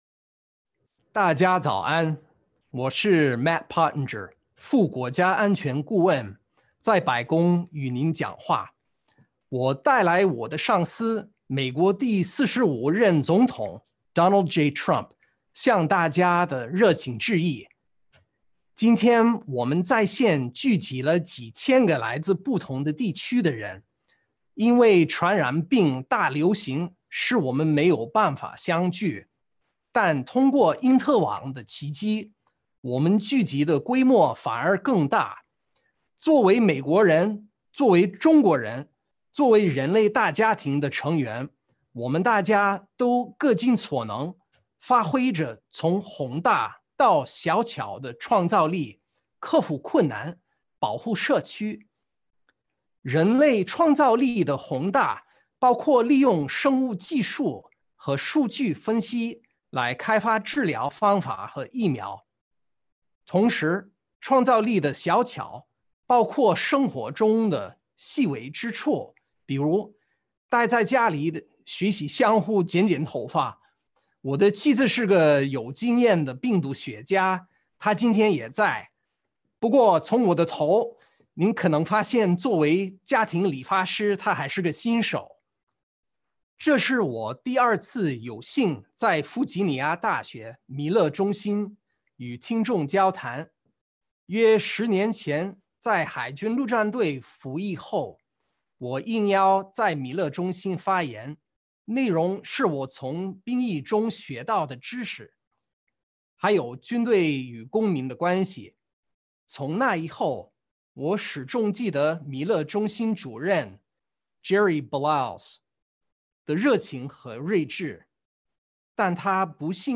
白宫高官史上首次中文演说：博明《 一个美国视角下的中国“五四”精神》全文实录
美国副国家安全顾问博明(Matt Pottinger)5月4日在中国五四运动101周年之际在白宫通过联线方式参加了弗吉尼亚大学米勒中心有关美中关系的在线研讨会并用中文发表题为《一个美国视角下的中国“五四”精神》的讲话。